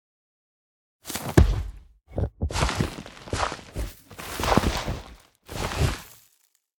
Minecraft Version Minecraft Version 1.21.5 Latest Release | Latest Snapshot 1.21.5 / assets / minecraft / sounds / mob / sniffer / longdig1.ogg Compare With Compare With Latest Release | Latest Snapshot
longdig1.ogg